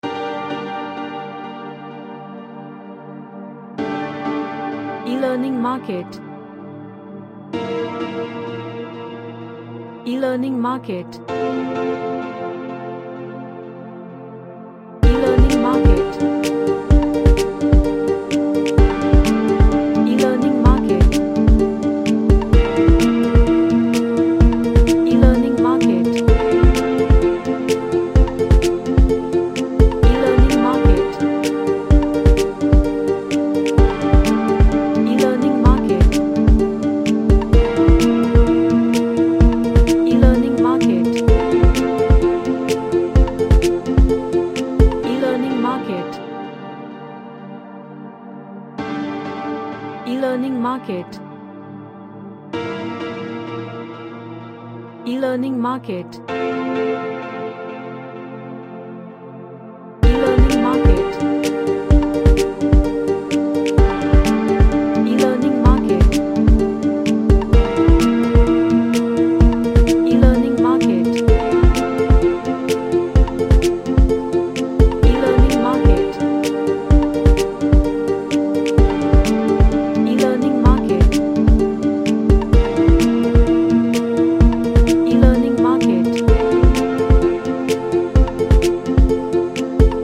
A track featuring harp and piano.
Happy